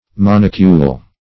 Search Result for " monocule" : The Collaborative International Dictionary of English v.0.48: monocule \mon"o*cule\ (m[o^]n"[-o]*k[=u]l), n. [See Monocular .]